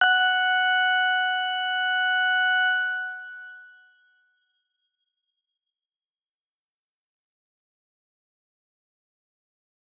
X_Grain-F#5-pp.wav